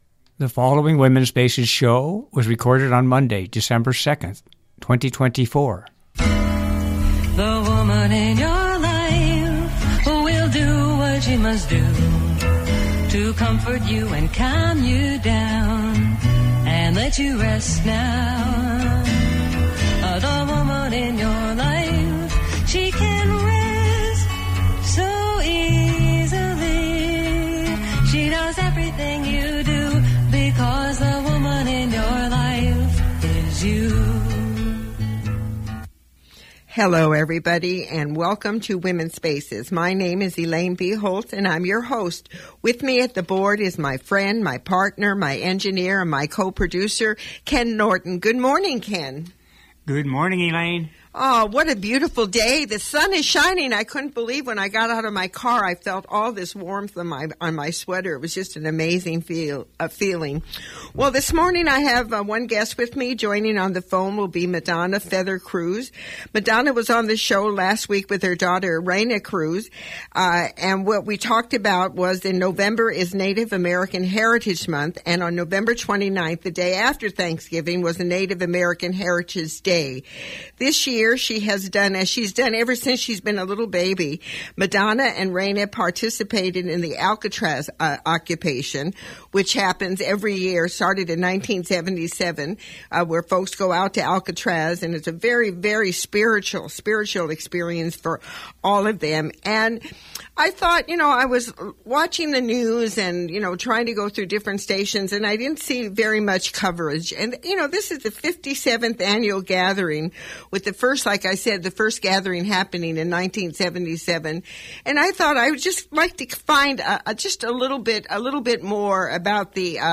I am going to play some special music and will be sharing some thoughts on what is going on in today’s world from my perspective. 2 .